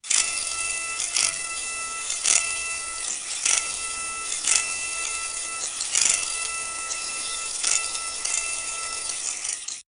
lightsaber katana vibrating sound
lightsaber-katana-vibrati-ktbngrsg.wav